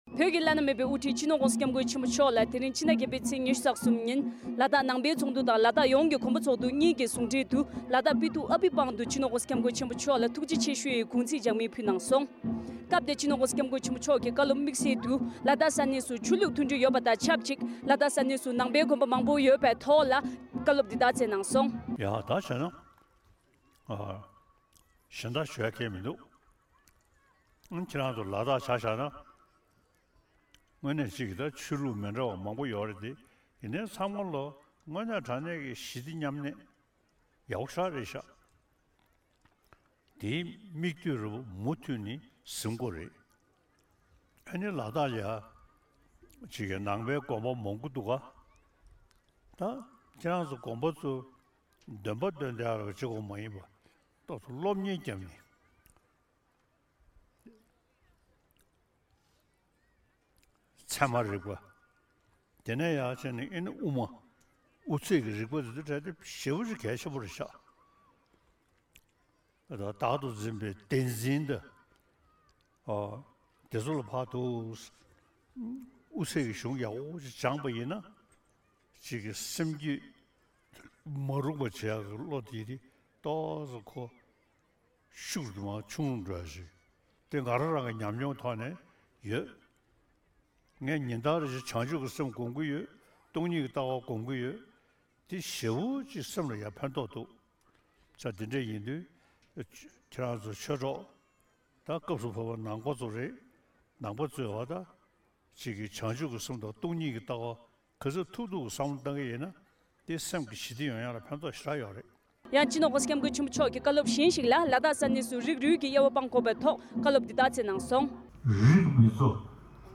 ༸གོང་ས་མཆོག་གིས། ལ་དྭགས་ནང་ཆོས་ལུགས་ཚང་མ་ཞི་བདེ་མཉམ་གནས་ཡོད་པ་དེ་ཡག་པོ་ཞེ་དྲགས་རེད་འདུག ཅེས་བཀའ་སྩལ་བ། ༸གོང་ས་མཆོག་ལ་ཐུགས་རྗེ་ཆེ་ཞུའི་དགུང་ཚིགས་ལྗགས་སྨིན་འབུལ་བའི་སྐབས། ༢༠༢༣།༠༨།༢༣ ཉིན།
སྒྲ་ལྡན་གསར་འགྱུར།